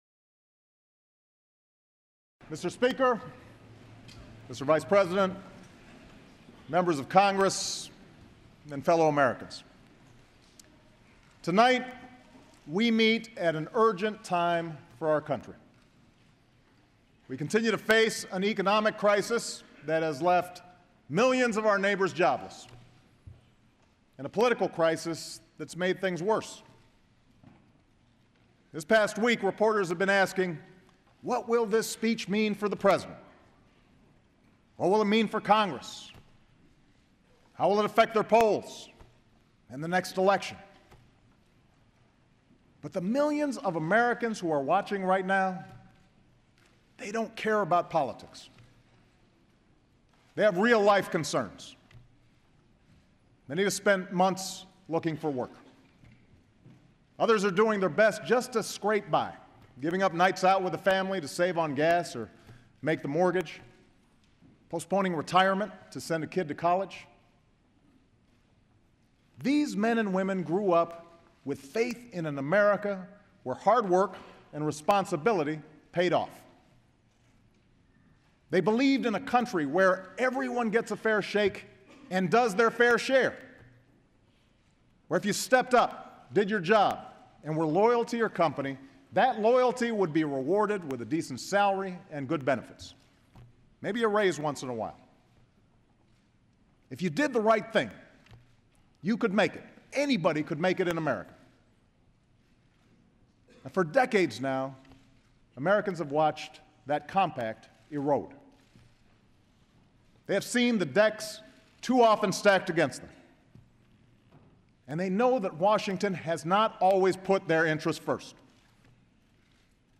U.S. President Barack Obama speaks to a joint session of Congress on job creation and taxation
President Barack Obama addresses a joint session of Congress and proposes the creation of the "American jobs act". Obama says that nothing in the proposed legislation is controversial, touts tax breaks for companies that create jobs, payroll tax cuts for both workers, and investment in rebuilding infrastructure to put construction workers back to work. Obama also recommends budget cuts in some areas as well as a tax increase on the wealthiest in America and challenges Congress to pass the bill which is already supported by the Chamber of Commerce and the AFL-CIO.